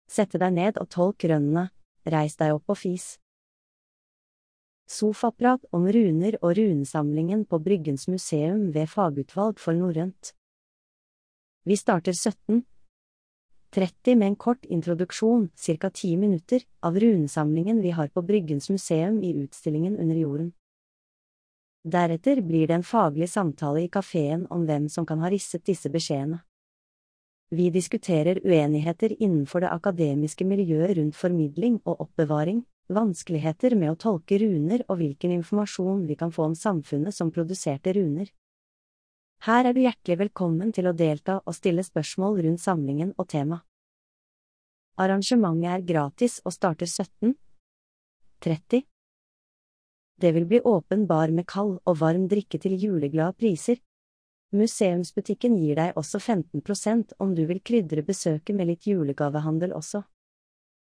Sofaprat om runer og runesamlingen på Bryggens Museum ved Fagutvalg for norrønt.